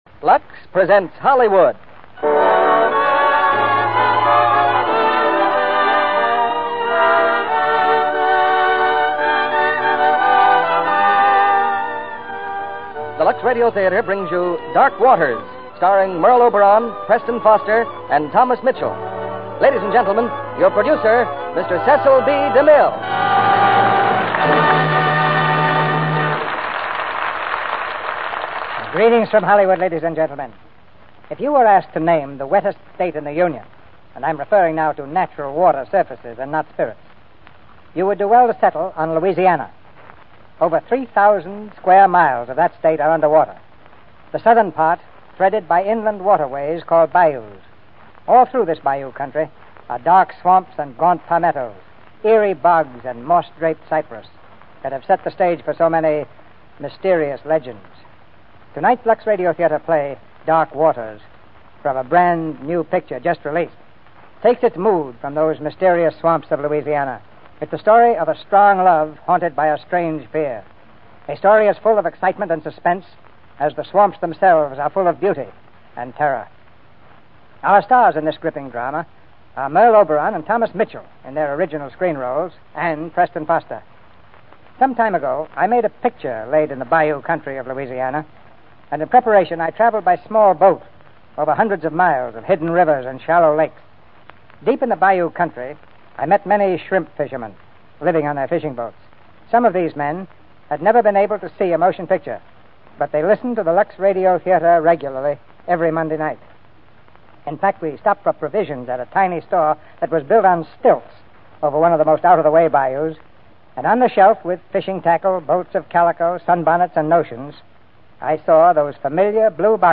Dark Waters, starring Merle Oberon, Preston Foster, Thomas Mitchell